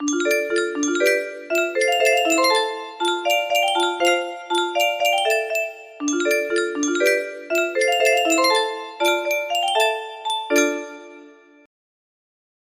Full range 60
Fixed chords in the middle part.